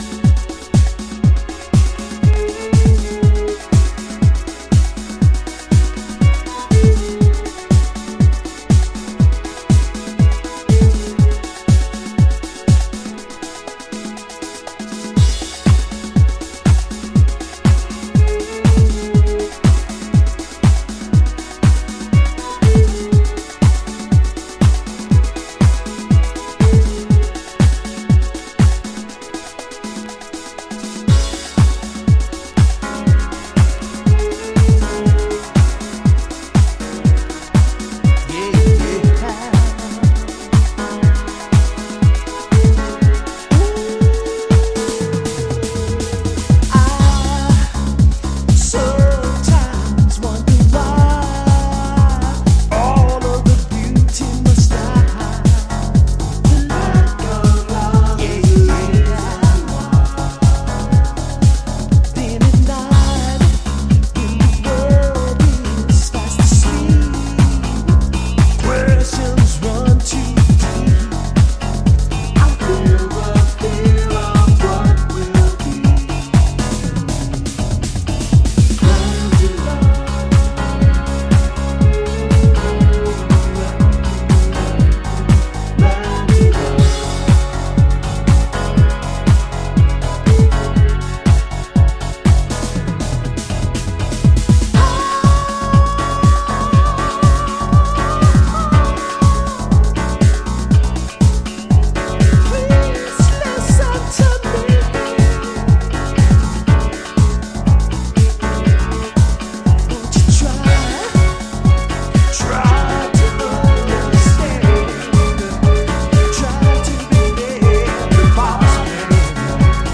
噎せ返るようなCHI-HOUSE。